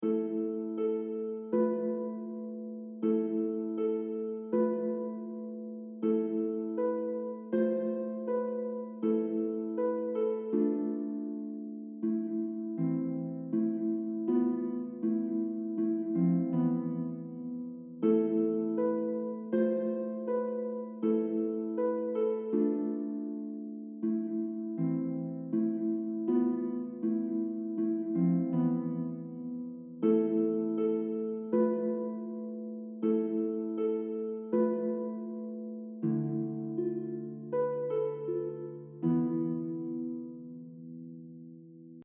traditional Japanese folk song
for solo lever or pedal harp
• Easy harp solo: $4.00
Easy version: